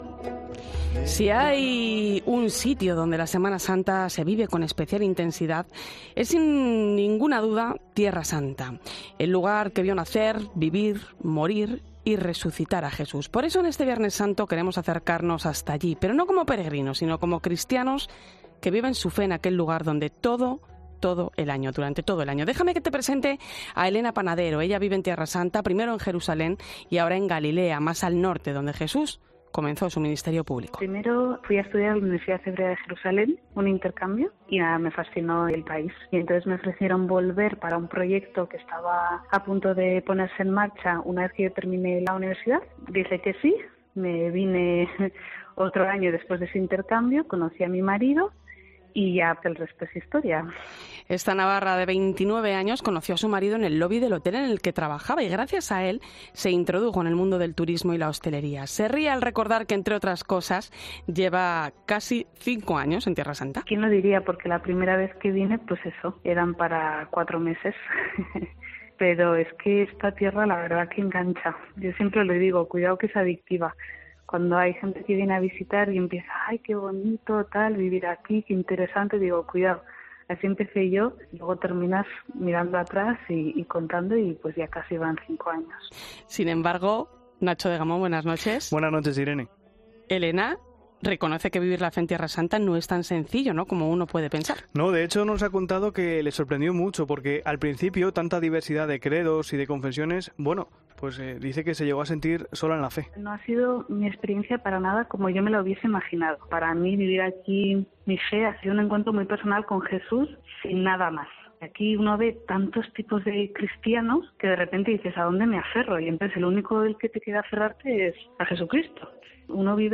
Una española explica cómo se vive la Semana Santa en el lugar que vio morir y resucitar a Jesús